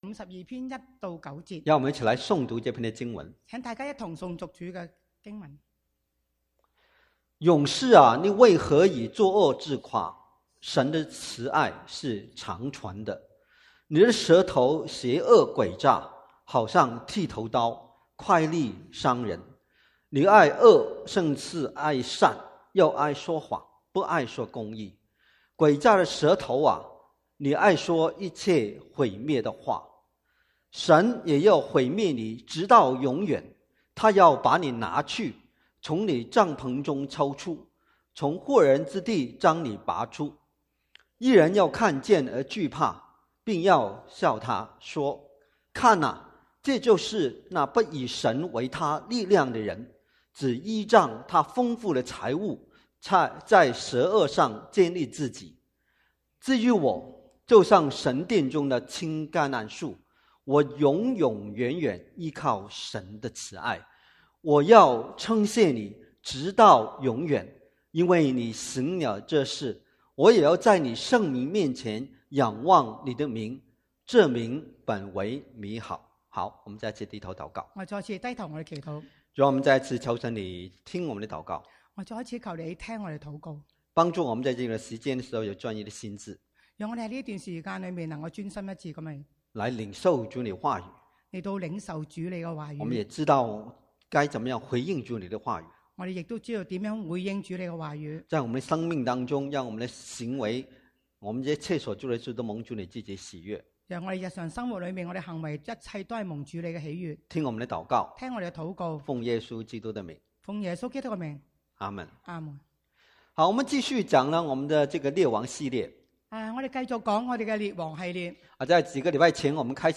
A message from the series "English Sermons."